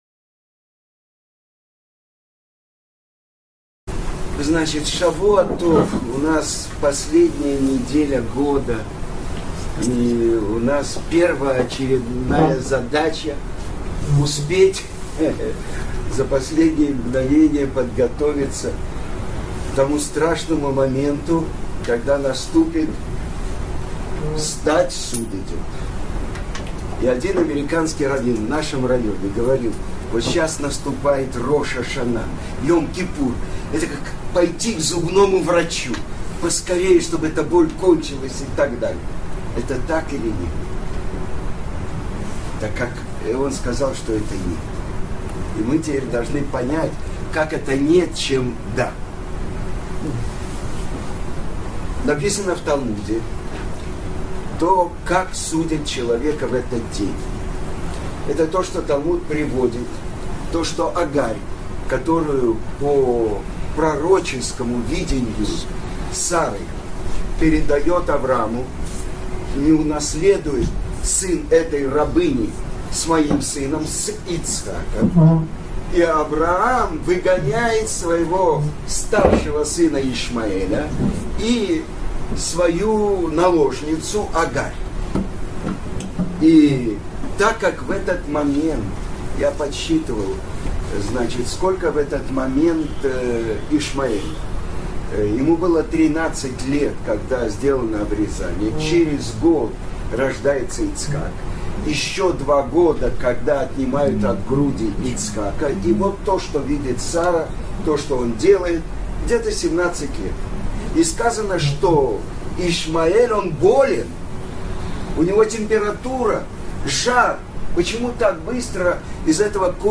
Чудеса нашего времени. Урок
на семинаре Беерот Ицхак в Цюрихе (сентябрь 2018).